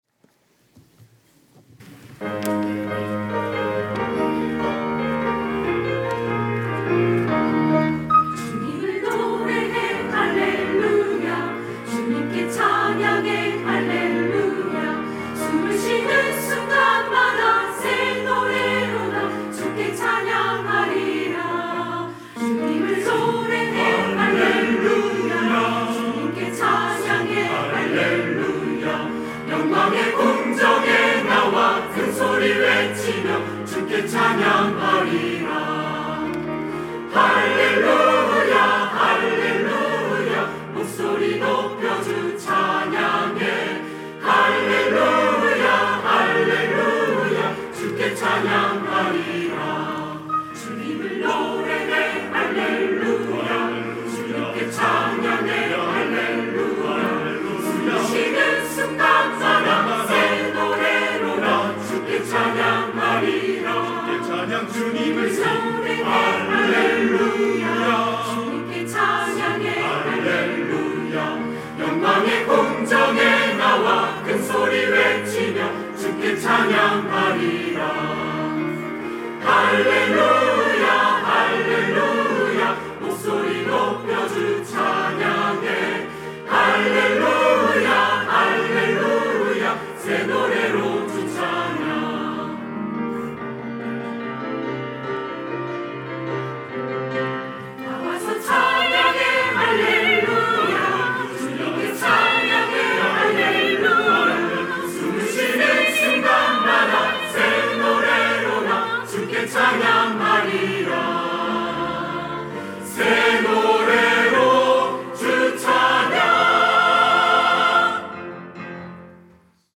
할렐루야(주일2부) - 주님을 노래해 할렐루야!
찬양대